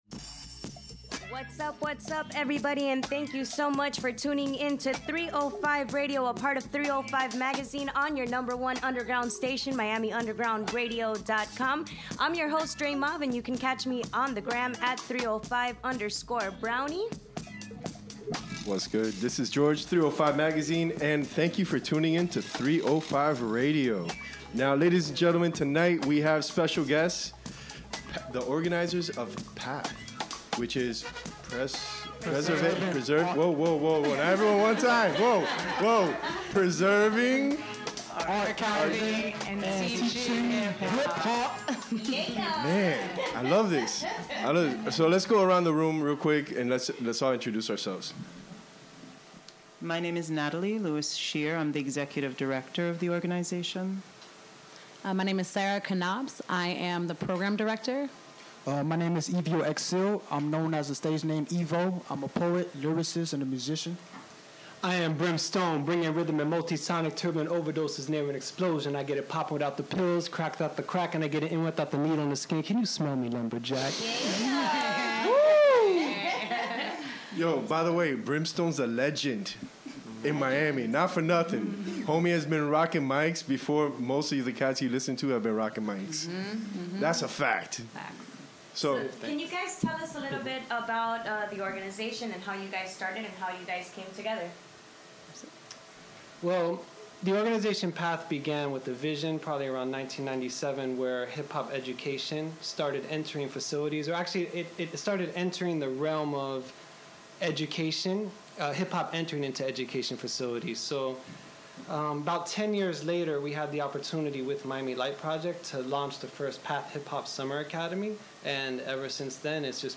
We have the organizers of P.A.T.H. in the studio and we chop it up about their community Hip-Hop program. Teaching the fundamentals of the culture thats transformed our landscape.